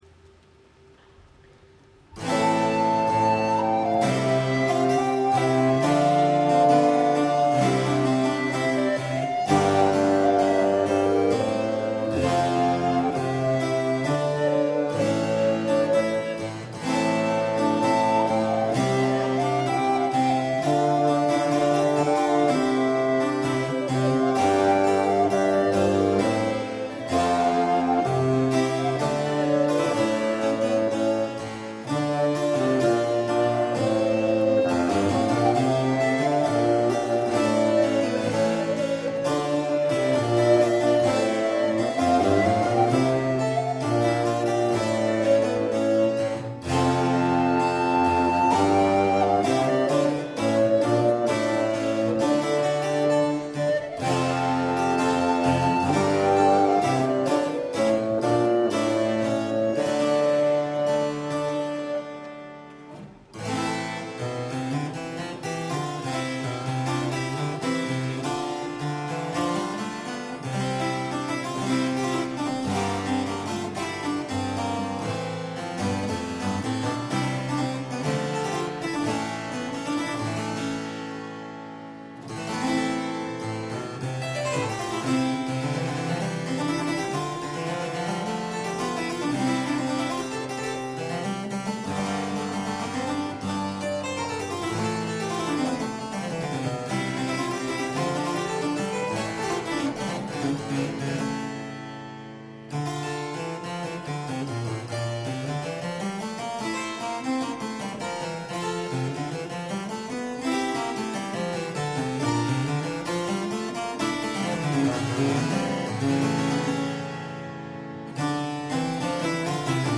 Registrazioni LIVE
Templo de la Valenciana - Guanajato-Mexico - Festival Internacional Cervantino, 4 ottobre 2007
CONSORT VENETO:
soprano
flauto
Dulciana
cembalo
Registrazione a Cura di Radio Educàtion Mexico